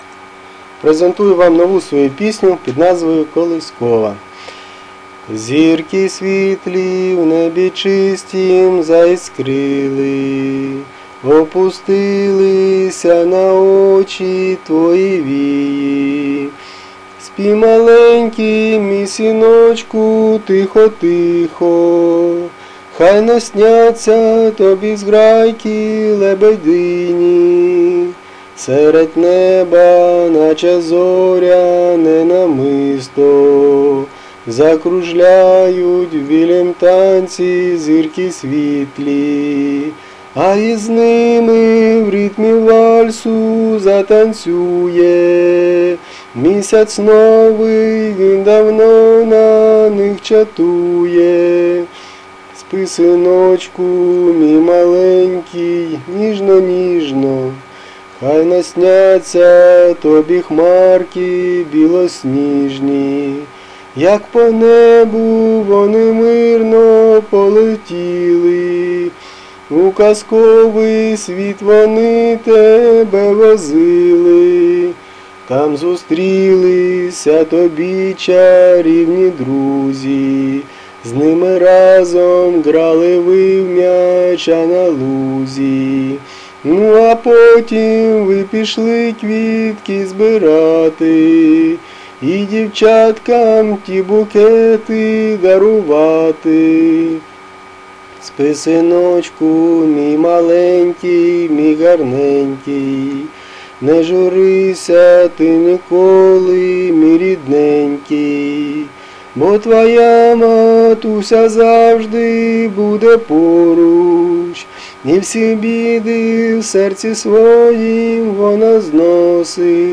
**** Колискова **** ( Пісня )
Рубрика: Поезія, Авторська пісня